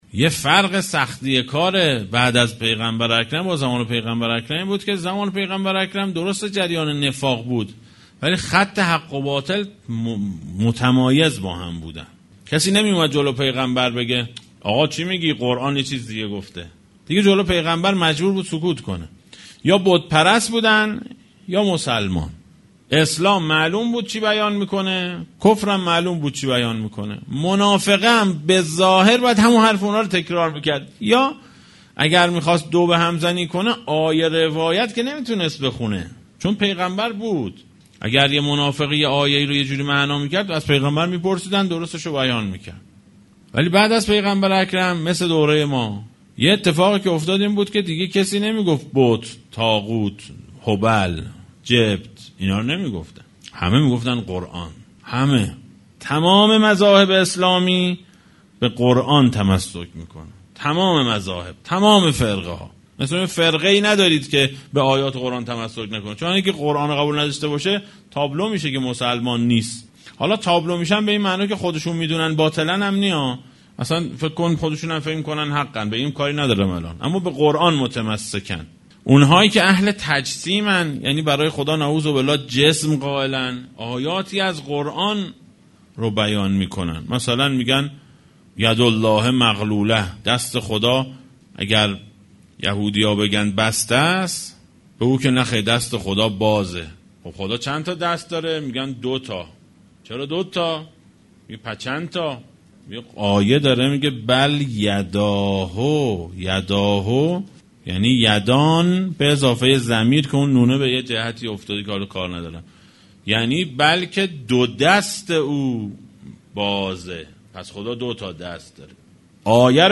برگرفته از جلسات “درنگی بر یکی از جملات زیارت جامعه کبیره”